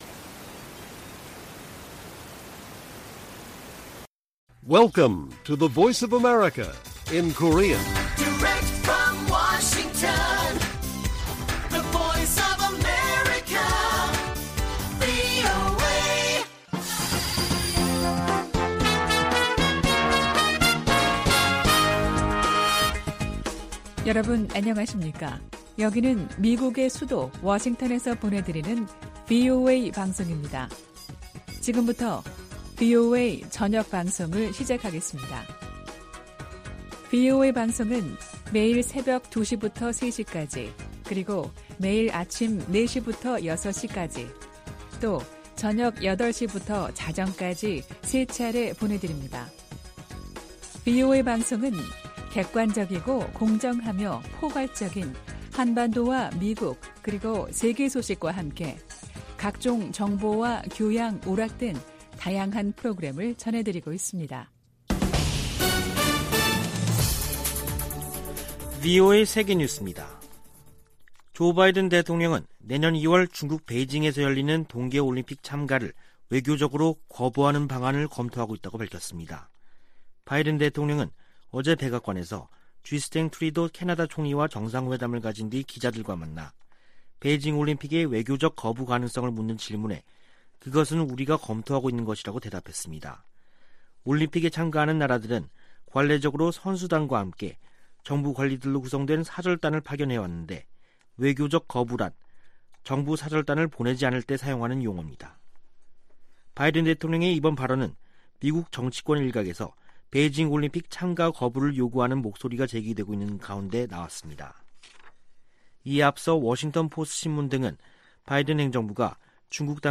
VOA 한국어 간판 뉴스 프로그램 '뉴스 투데이', 2021년 11월 19일 1부 방송입니다. 미국과 한국, 일본의 외교 당국 2인자들의 공동 기자회견이 무산된 데 대해 미국의 전문가들은 삼각 공조의 어려움을 보여준다고 평가했습니다. 조 바이든 미국 대통령은 베이징 동계올림픽의 ‘외교적 보이콧’을 고려하고 있다고 밝혔습니다.